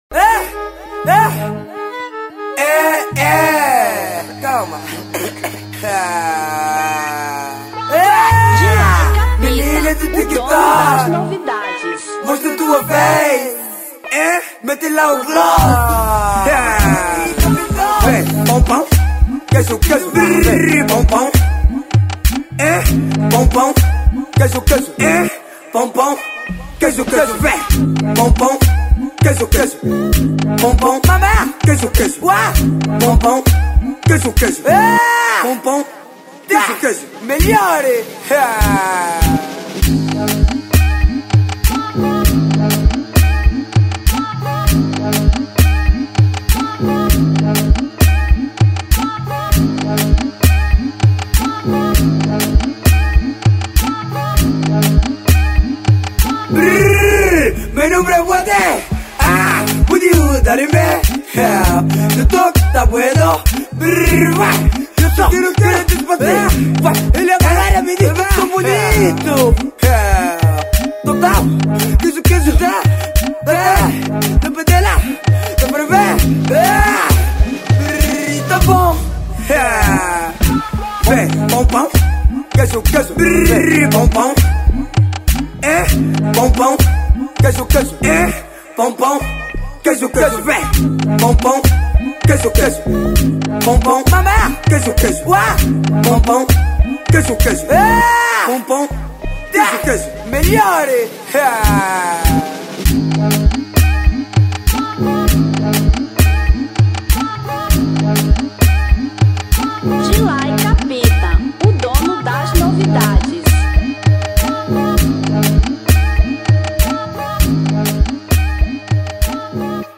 Tarraxinha 2025